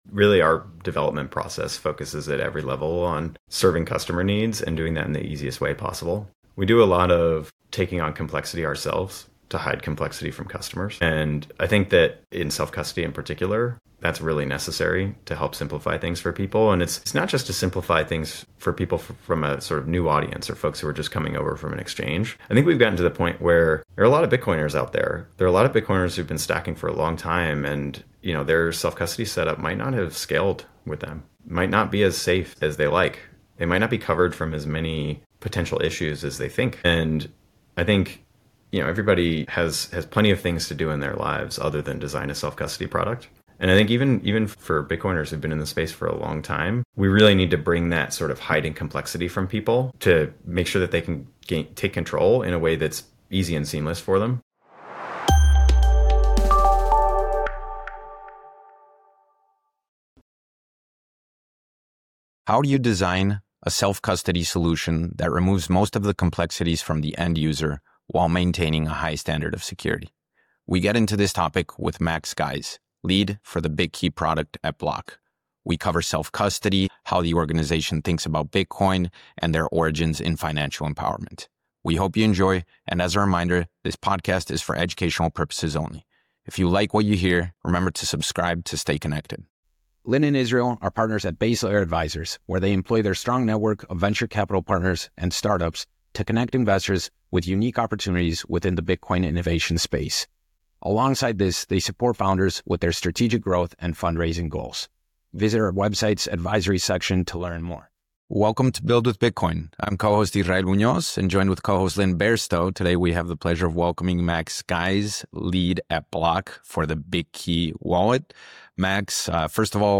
The conversation also touches on inheritance planning, market adoption, and the future roadmap for Bitkey, emphasizing the need for user feedback and the commitme